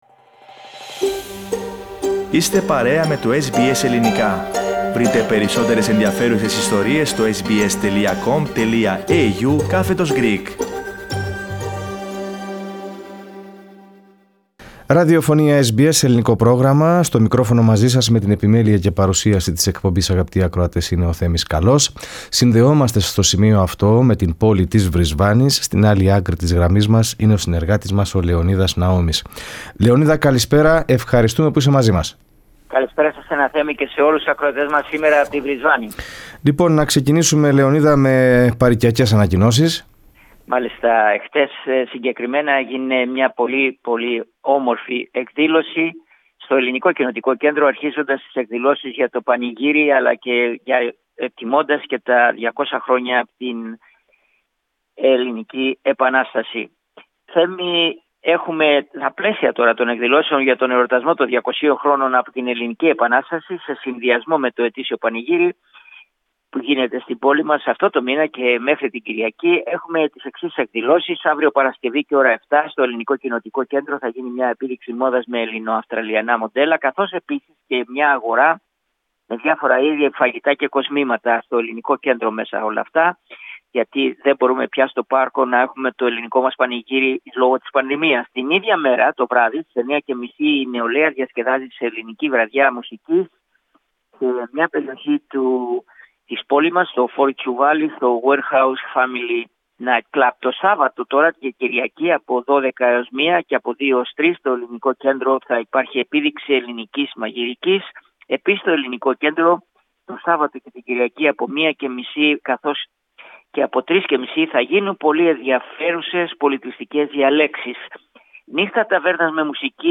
Η καλλιέργεια σμέουρων οδήγησε έναν αγρότη να βάλει τα φυτά ...στο ψυγείο! Ακούστε τις λεπτομέρειες στην ανταπόκριση